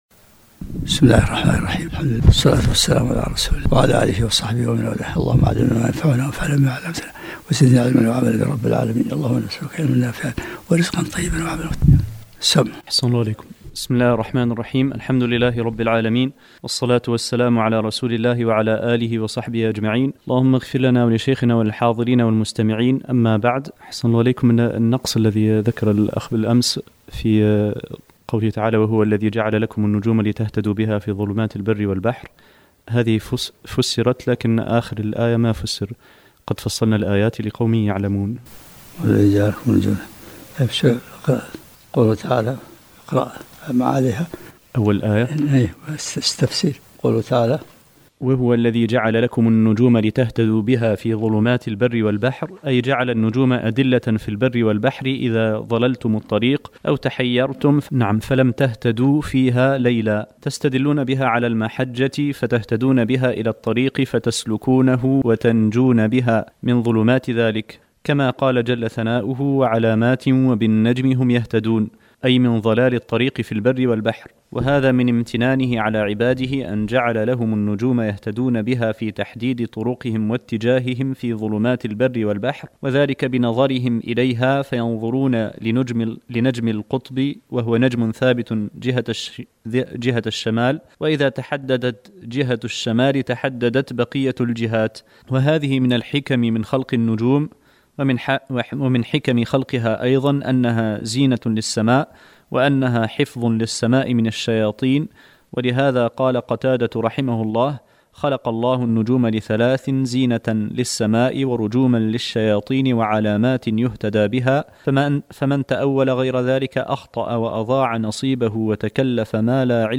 الدرس الثالث و العشرون من سورة الانعام